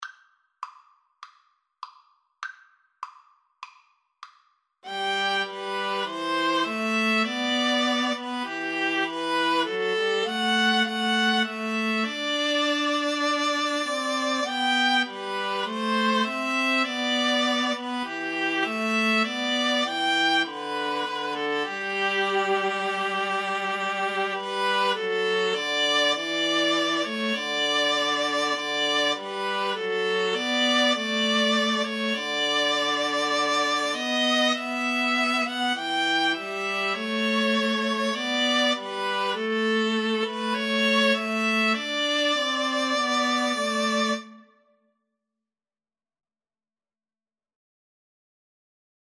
Free Sheet music for Viola Trio
G major (Sounding Pitch) (View more G major Music for Viola Trio )
4/4 (View more 4/4 Music)
Classical (View more Classical Viola Trio Music)